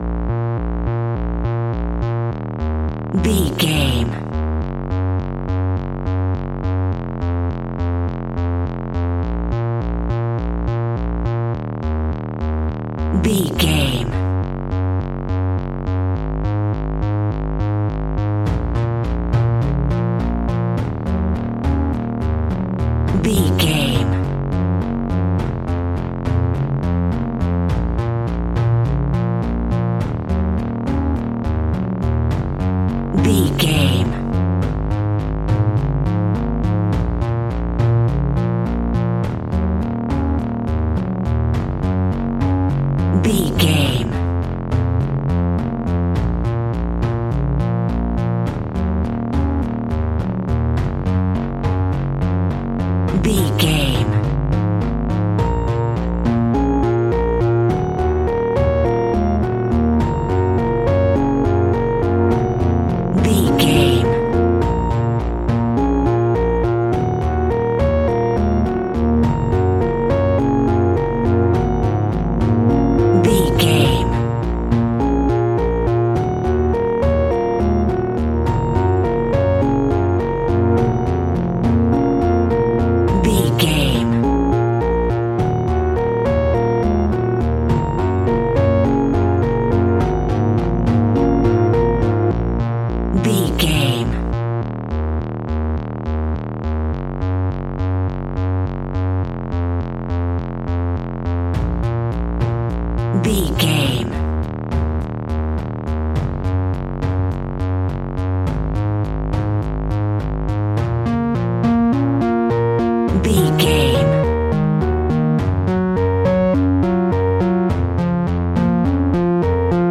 Thriller
Aeolian/Minor
B♭
piano
synthesiser